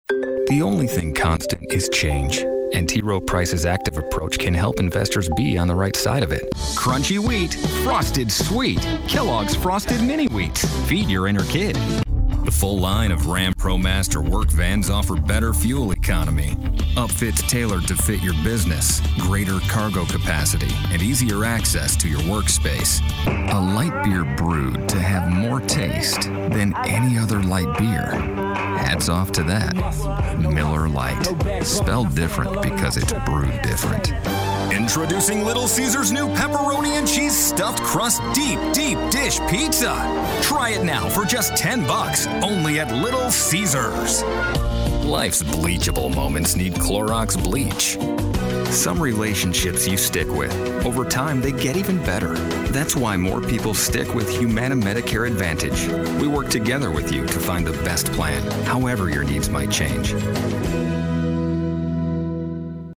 Guy-Next-Door, Upbeat, Friendly